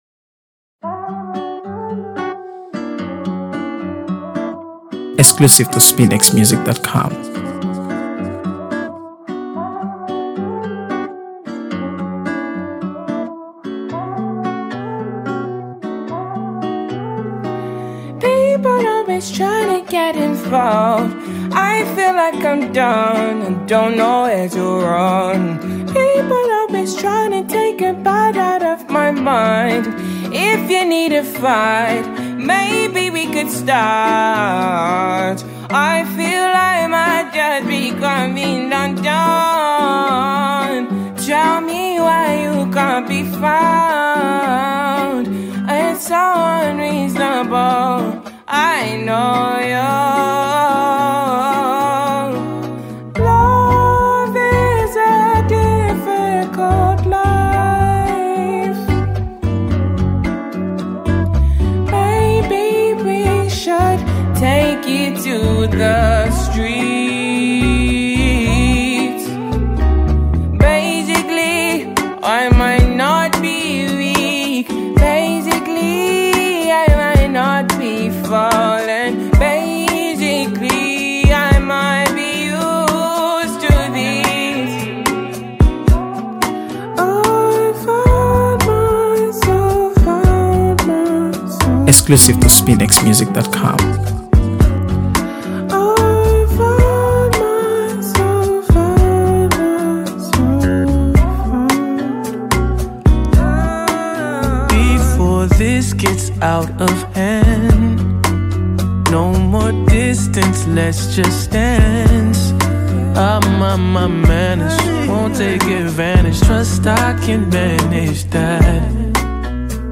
AfroBeats | AfroBeats songs
enchanting track
soulful vocals